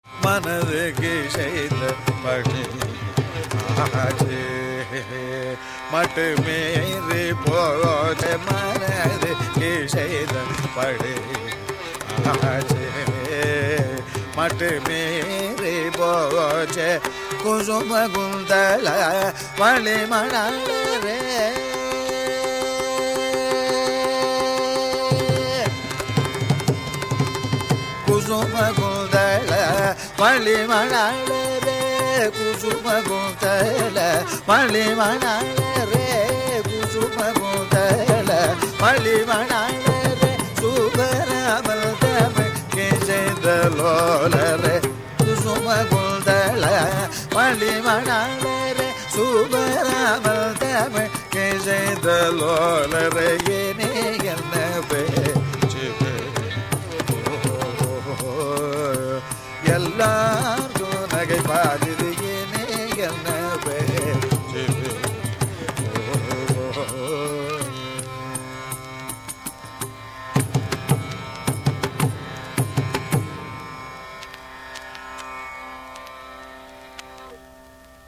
Rāga Śahāna
caranam